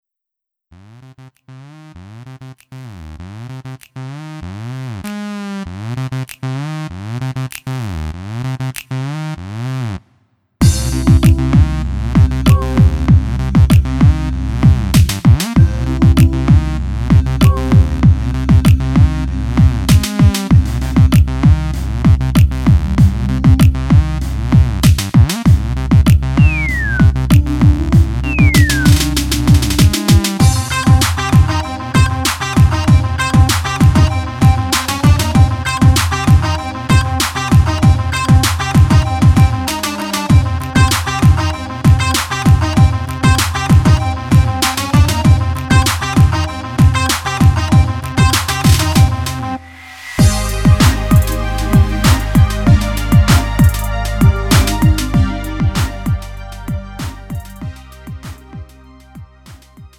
음정 원키 3:13
장르 구분 Lite MR